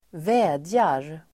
Uttal: [²v'ä:djar]